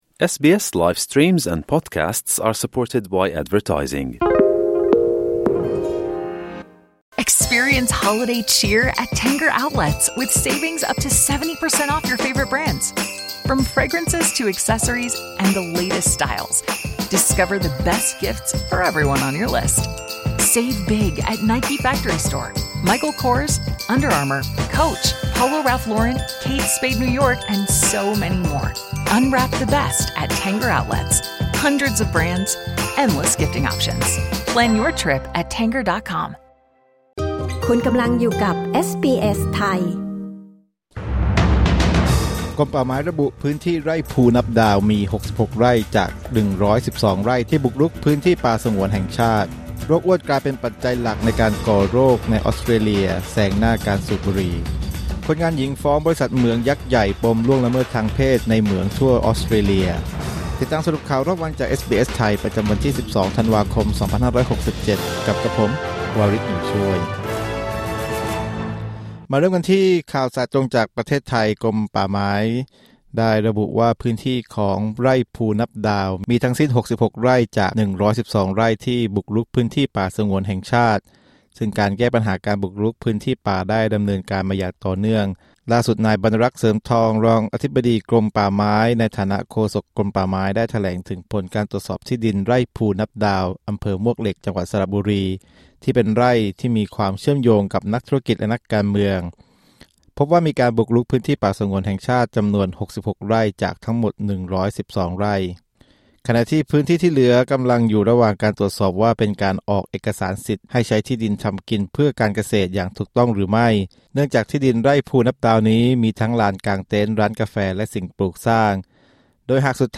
สรุปข่าวรอบวัน 12 ธันวาคม 2567